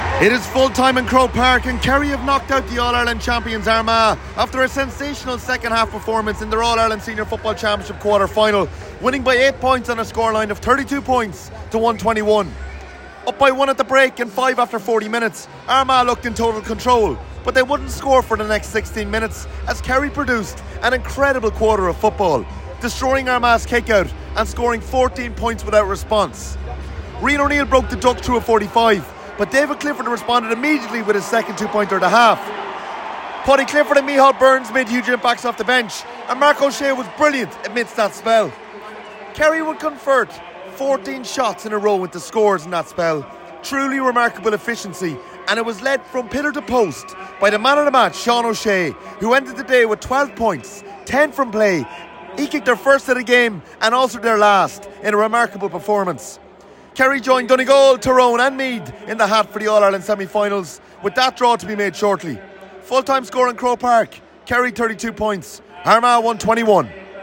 full-time report